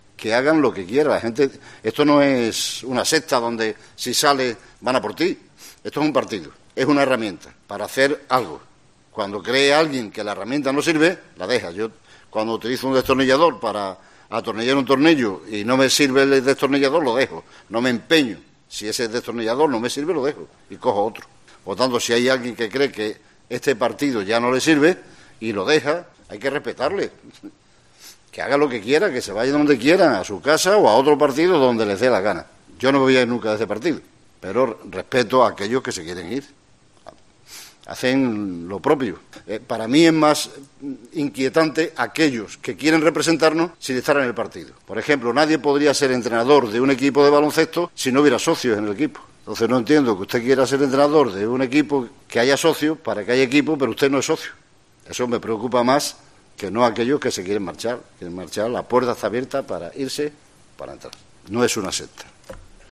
El expresidente extremeño, Juan Carlos Rodríguez Ibarra, se explaya cuando se le pregunta sobre la situación del PSOE, con idas, venidas y fichajes.
Unas declaraciones que Ibarra realizaba en trascurso de la presentación de un convenio firmado entre su Fundación, el Centro de Estudios Presidente Rodríguez Ibarra con Fundación CB mediante el cual dos estudiantes serán contratados por la primera para trabajar en materia de documentación de archivos, de experiencia y digitalización.